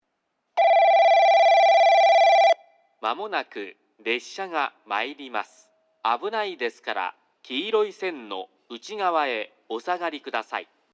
2番のりば接近放送　男声